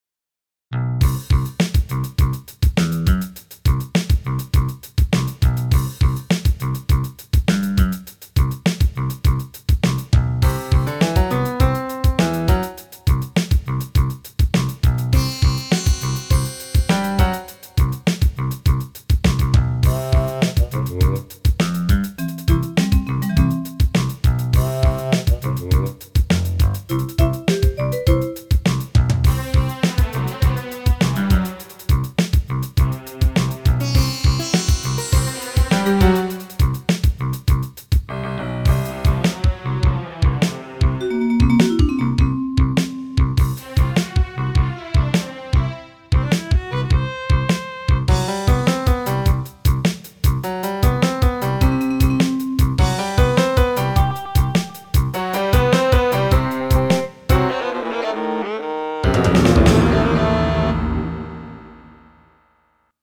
I was listening to an Eritrean tune based on the Lydian mode, which inspired me to use this scale myself.
FUNK ROCK MUSIC ; ODD MUSIC